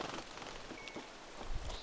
punatulkun ääni
tuollainen_punatulkun_aani.mp3